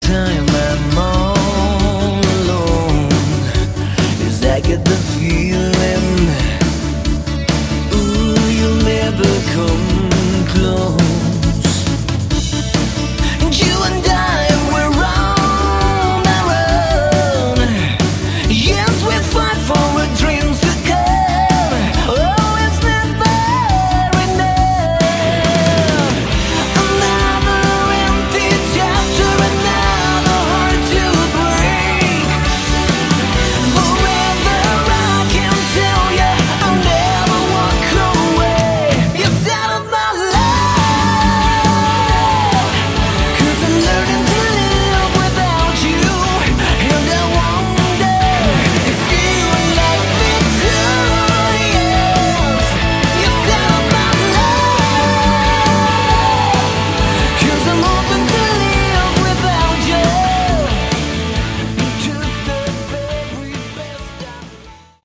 Category: AOR/Melodic Rock
vocals
guitars, bass, Keys, drums.